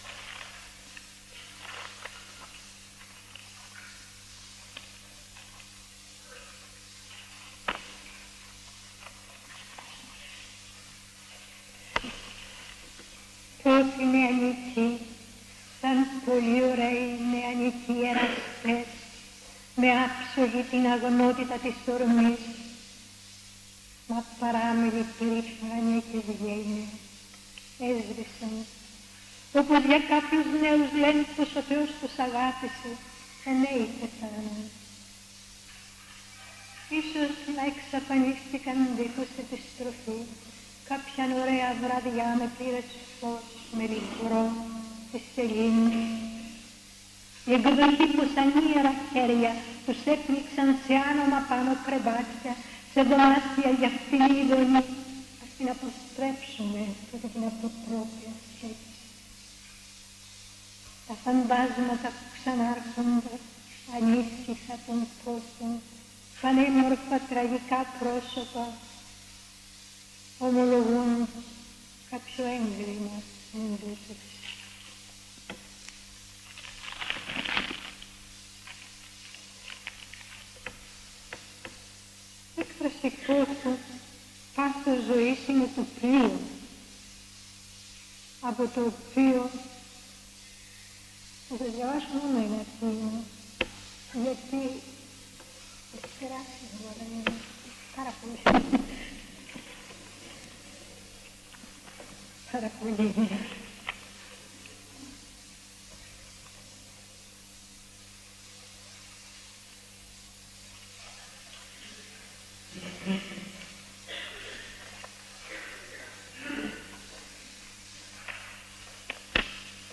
Εξειδίκευση τύπου : Εκδήλωση
Περιγραφή: Εισαγωγική Ομιλία του Γ. Π. Σαββίδη
Περίληψη: Η Ζωή Καρέλλη διαβάζει ποιήματά της